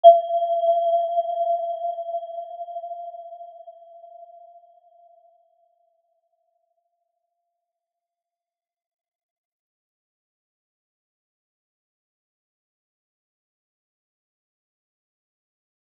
Aurora-G5-mf.wav